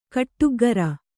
♪ kaṭṭuggara